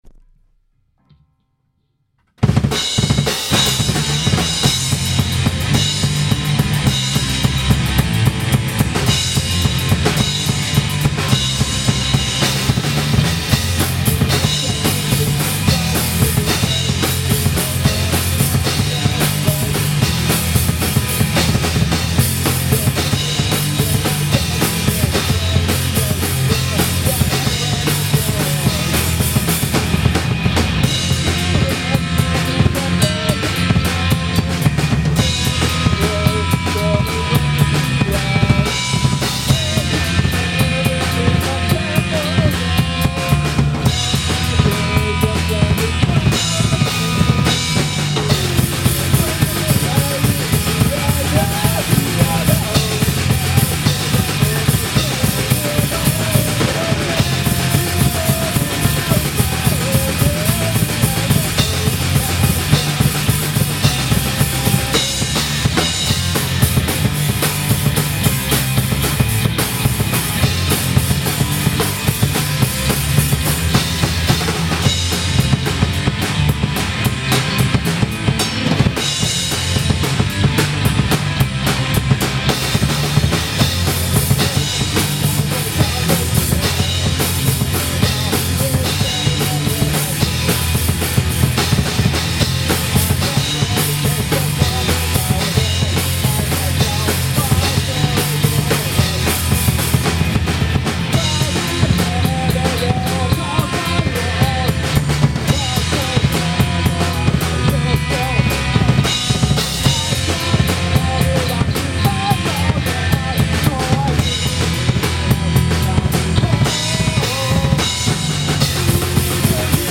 メロコアなのかパンクなのか、3ピースバンド
スタジオ一発録りのDEMO音源と、今までのLIVE動画。